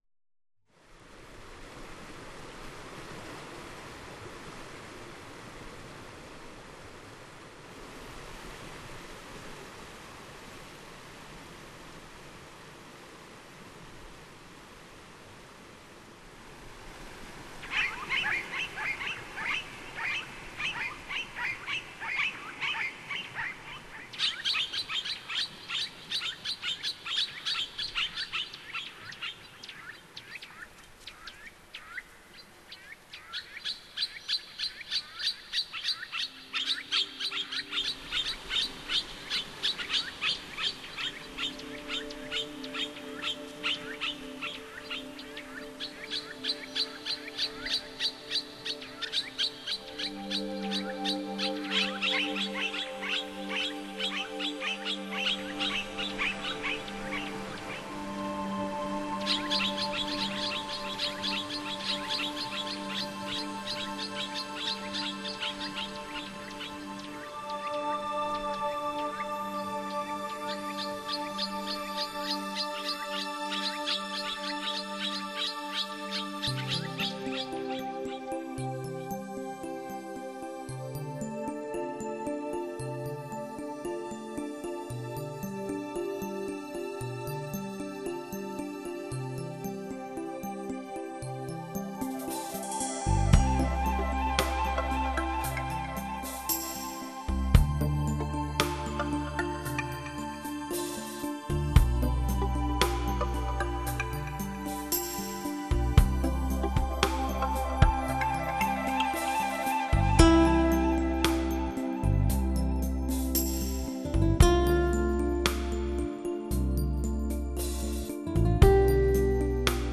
轻音乐